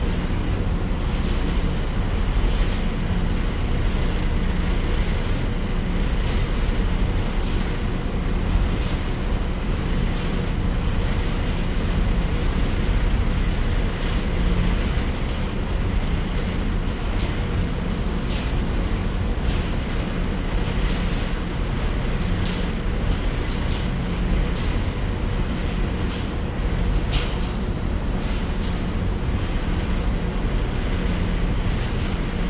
自助洗衣店 " 自助洗衣店的洗衣机发出响声震动2
描述：洗衣店洗衣机洗衣机拨浪鼓vibrate2.flac
Tag: 自助洗衣店 振动 拨浪鼓 洗涤 垫圈